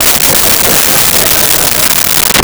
Crowd Laughing 07
Crowd Laughing 07.wav